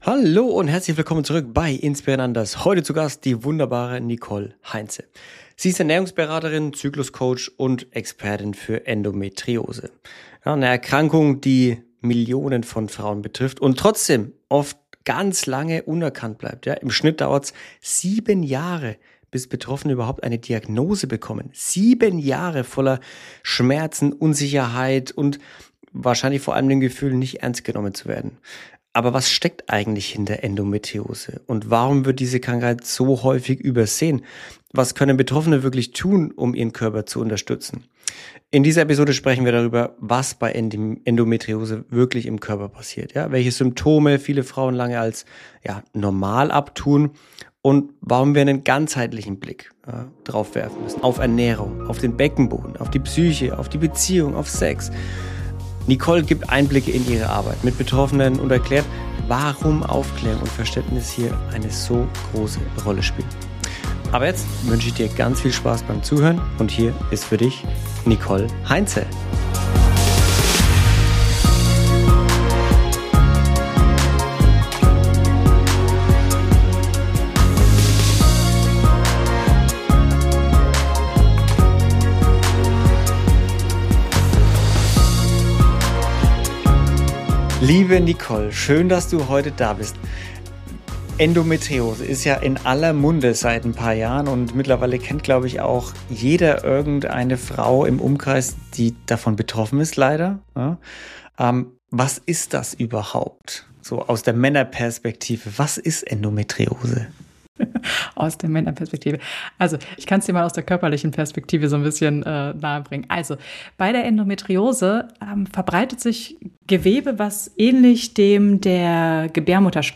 In dieser Folge zu Gast